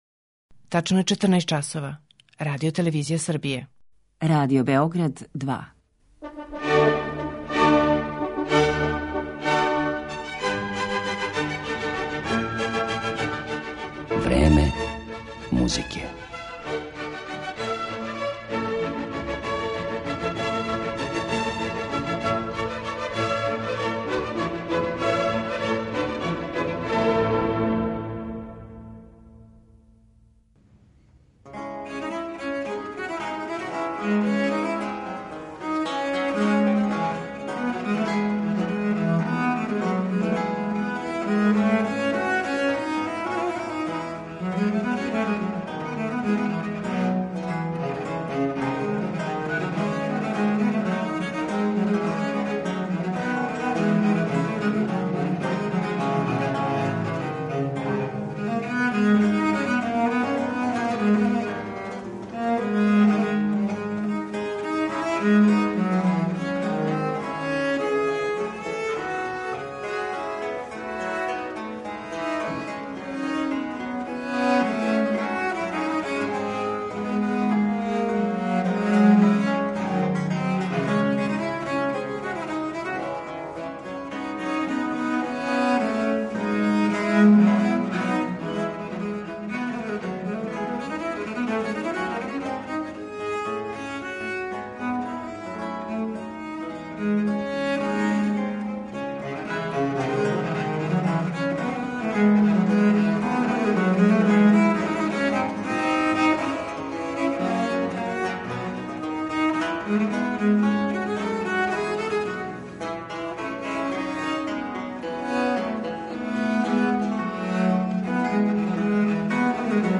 мајстор виоле да гамба